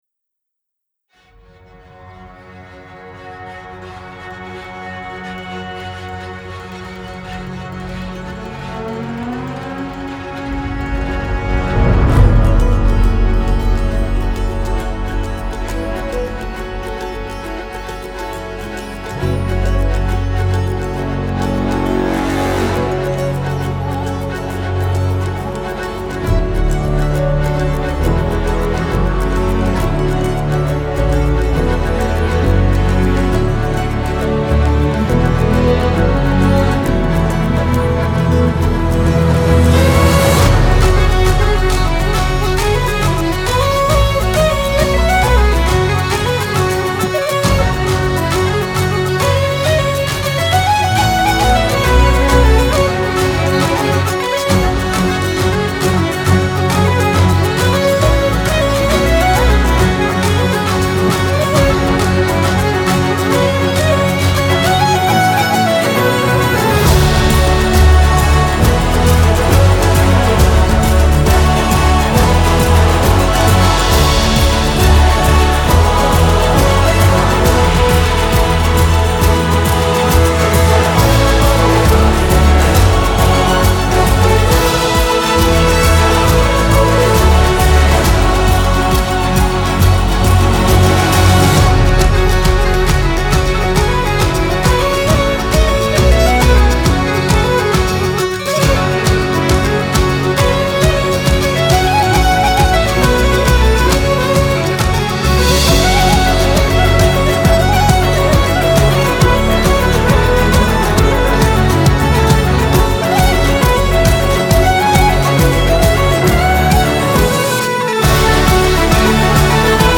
سبک اپیک , موسیقی بی کلام
موسیقی بی کلام سلتیک حماسی